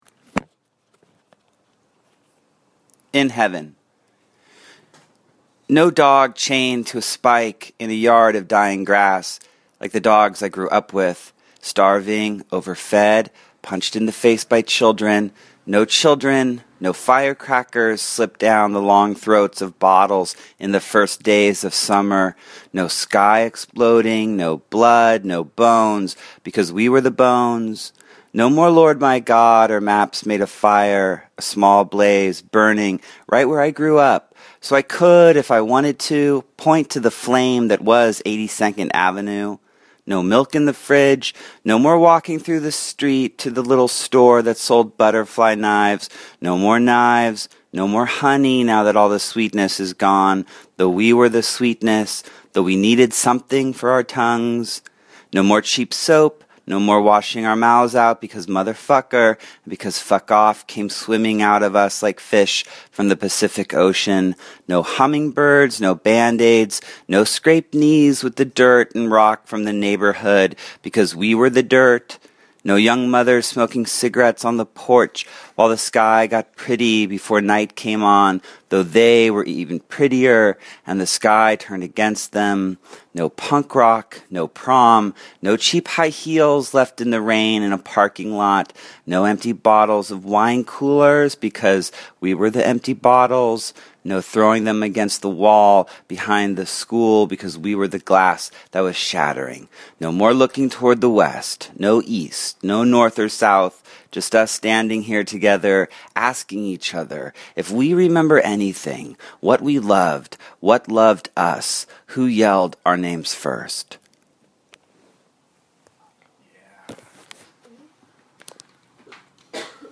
Poet Matthew Dickman reads "In Heaven" from his latest collection, Mayakovsky's Revolver, published in October by Norton.